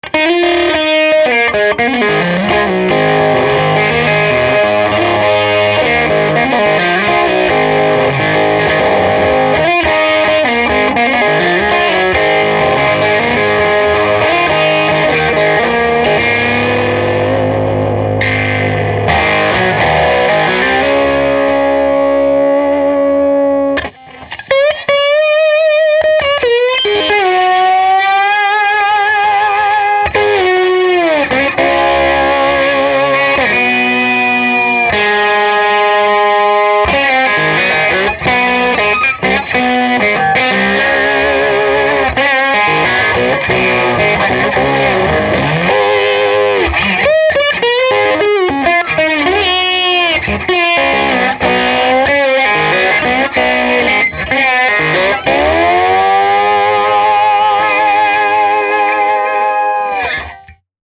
Studio 112 w Alnico Blue Dog, Les Paul / Kingsley Classic 30 (High Gain) Play Sample
Excellent companion to lowpowered amps 5-15W, providing choice of tasty low volume clean tone and great compressed high volume overdriven tones.